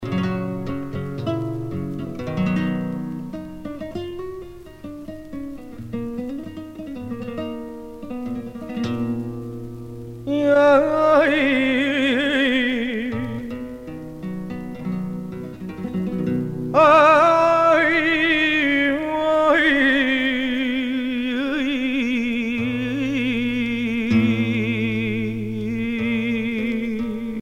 danse : flamenco
Pièce musicale éditée